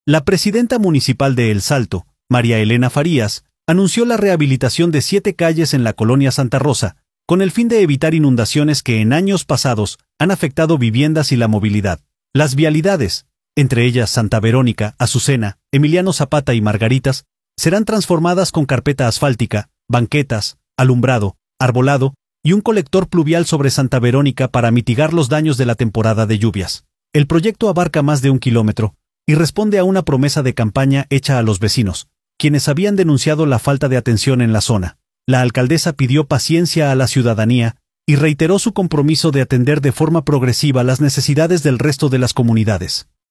La presidenta municipal de El Salto, María Elena Farías, anunció la rehabilitación de siete calles en la colonia Santa Rosa con el fin de evitar inundaciones que en años pasados han afectado viviendas y la movilidad.